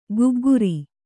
♪ gugguri